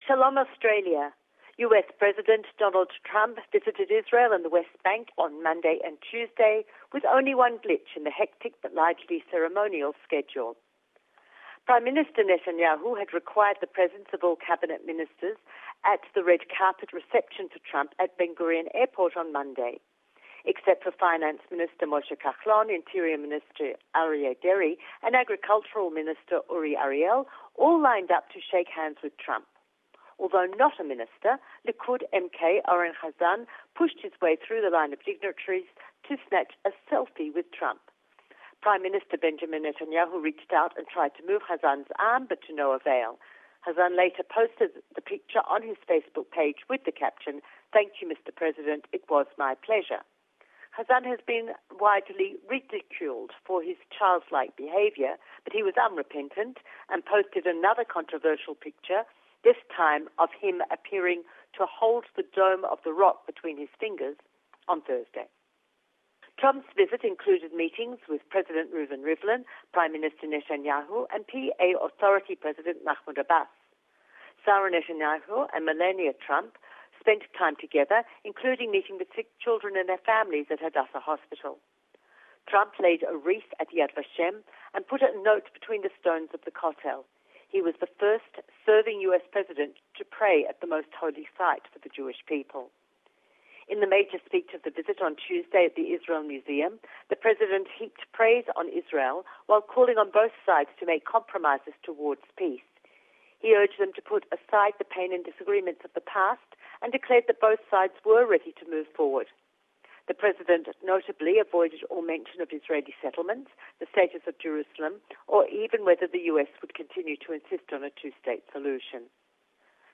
Don't miss our weekly report with the latest from Jerusalem